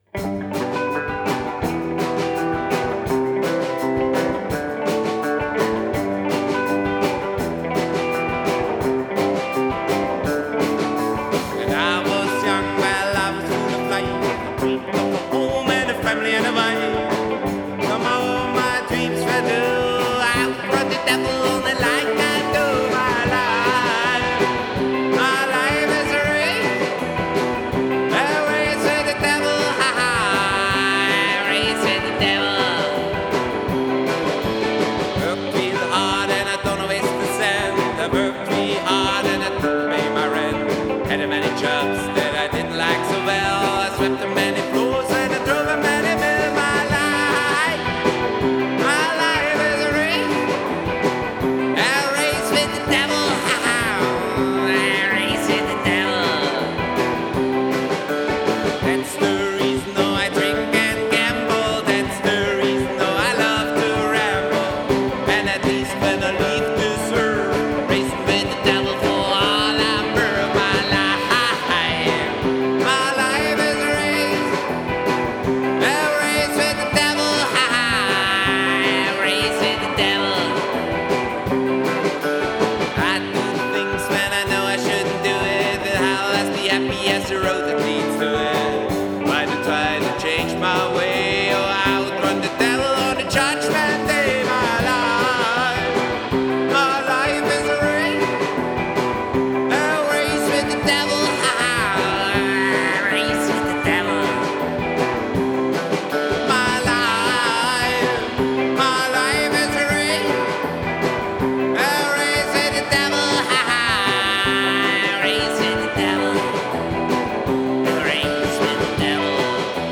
Genre: Psychobilly, Rockabilly, Country